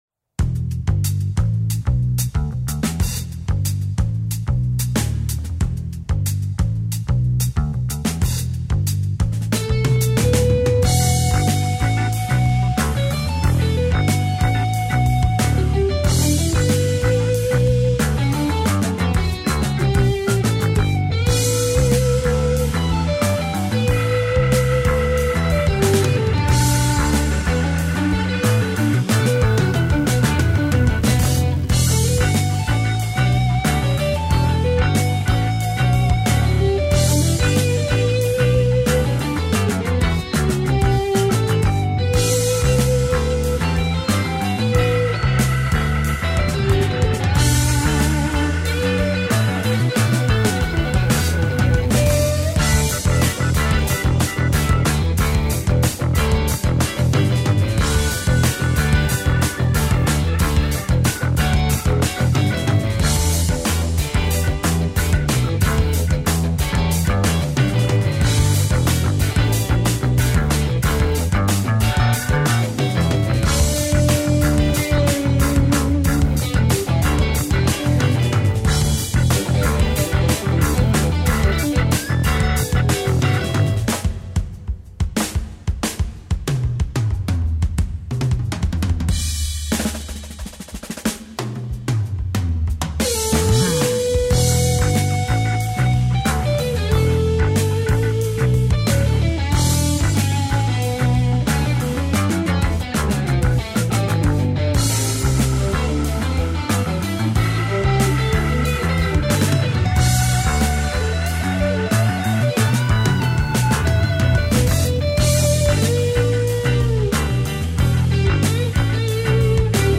WARM VINTAGE ELECTRIC BASS TONES
DEEP, WARM UND VIELSCHICHTIG